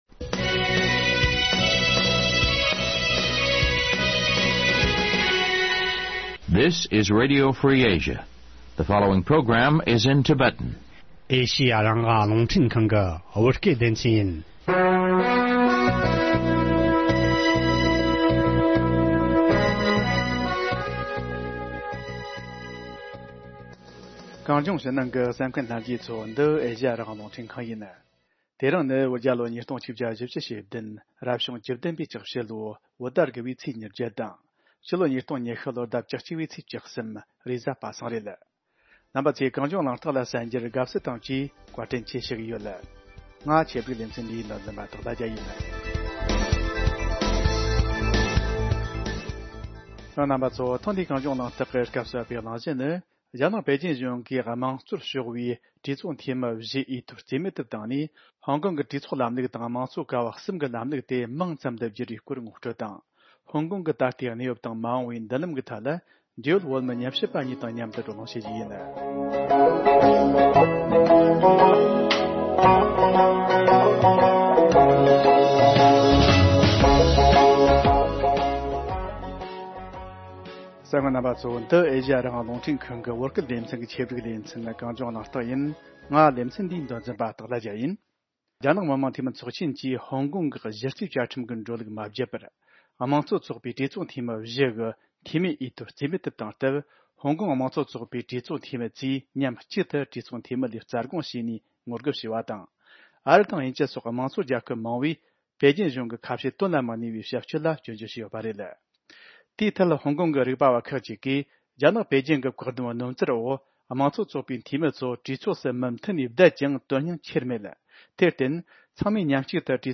བགྲོ་གླེང་བྱས་པར་ཉན་རོགས་གནོངས།།